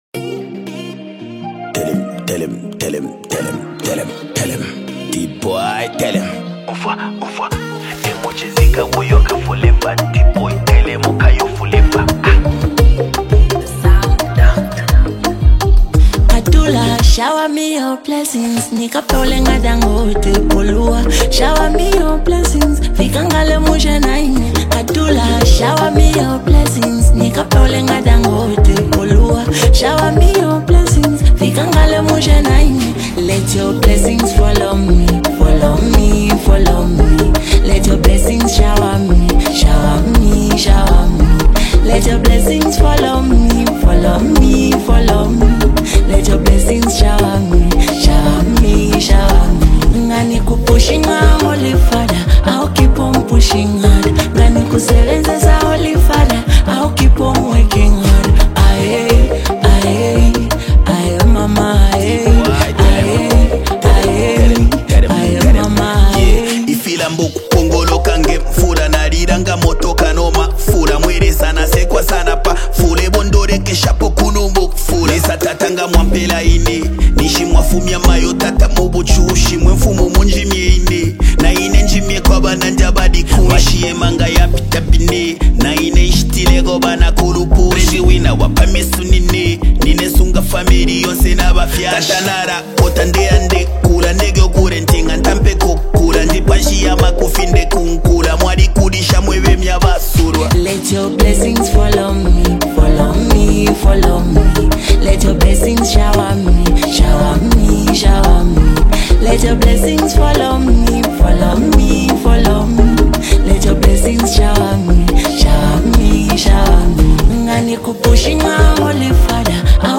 The exceptionally talented rapper
soulful and captivating track